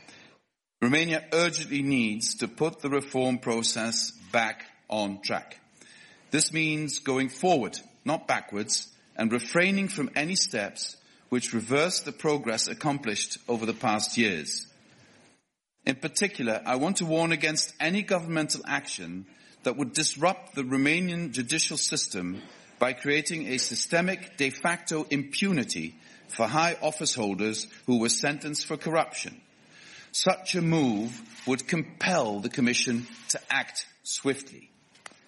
Într-o conferință de presă, Timmermans a avertizat dur România în legătură cu planurile guvernului social democrat – ALDE din domeniul justiției.
Declarația prim-vicepreședintelui Comisiei Europene, Frans Timmermans